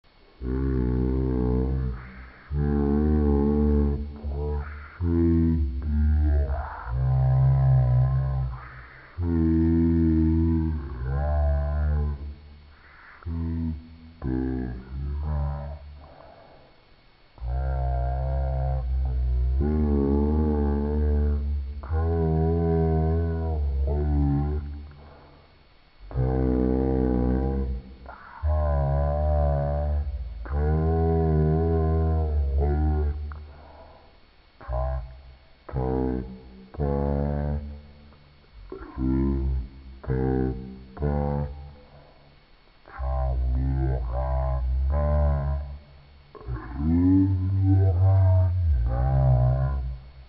Note pour jouer à la balle
L'enfance - Enfantines - rondes et jeux
Pièce musicale inédite